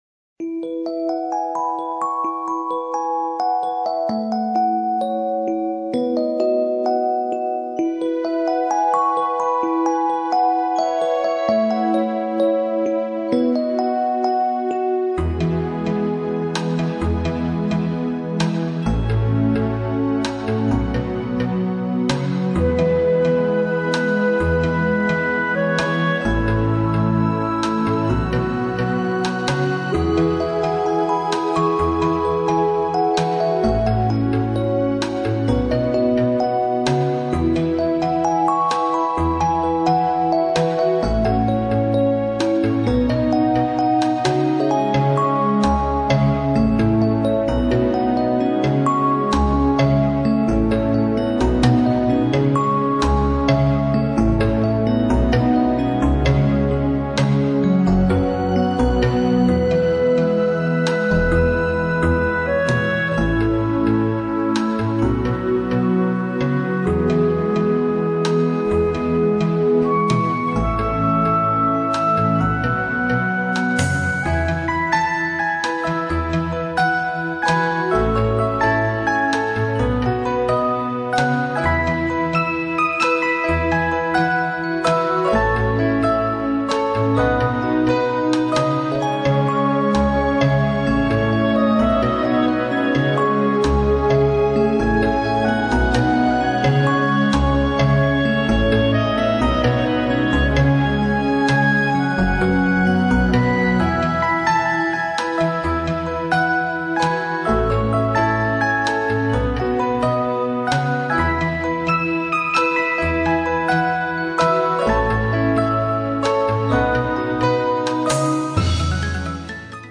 丰富跳跃的打击乐器，配上忽快忽慢的旋律